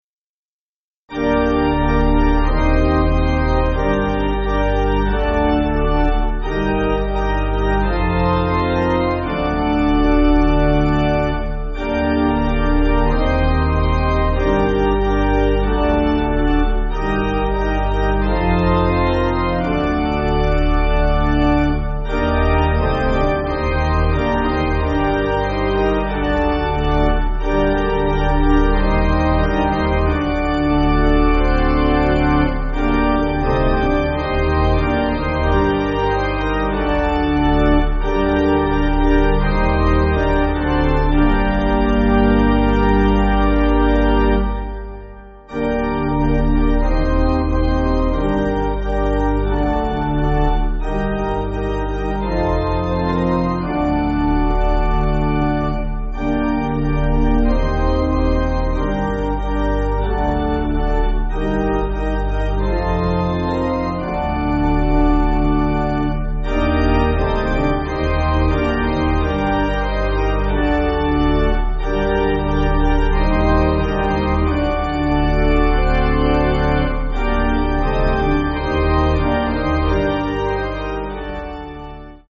Organ
(CM)   3/Gm